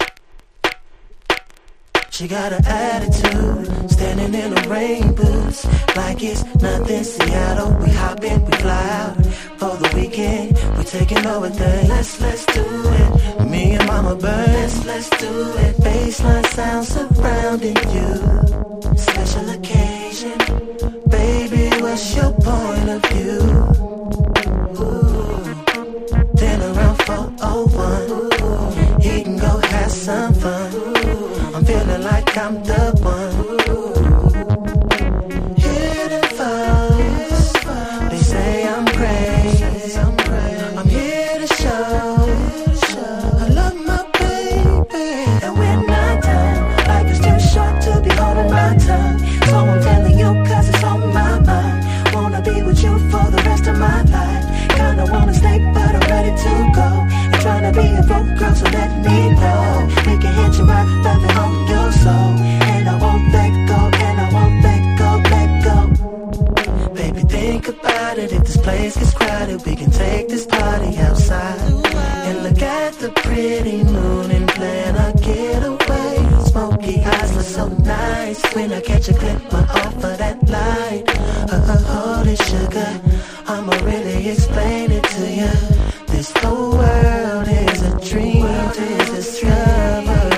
クロスオーヴァーな魅力に溢れたエレクトロ〜ビート・ダウンな2017年製フューチャーR&B！